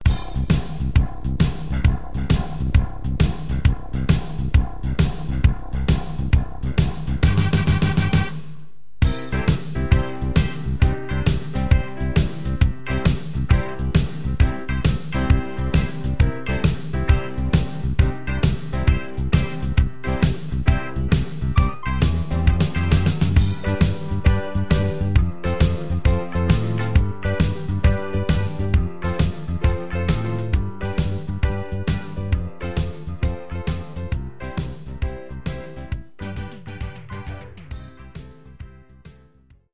This is an instrumental backing track cover.
• Key – C
• Without Backing Vocals
• No Fade